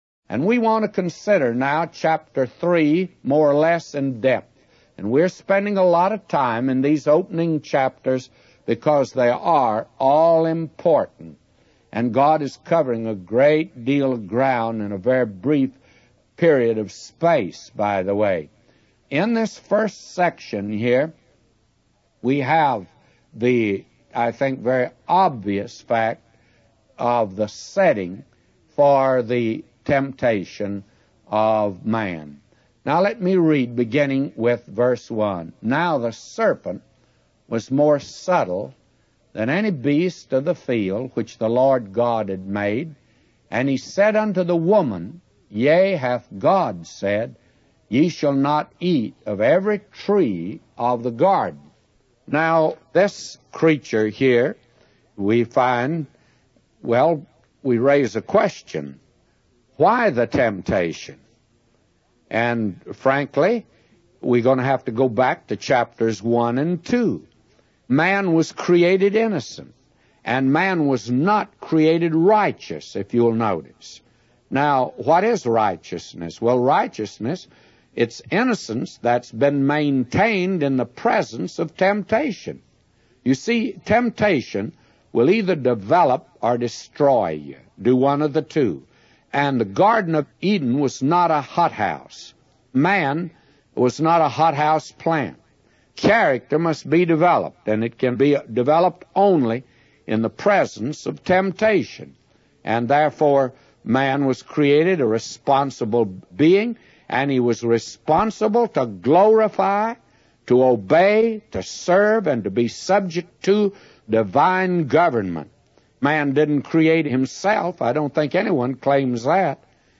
In this sermon, the preacher discusses the temptation and fall of man in the Garden of Eden.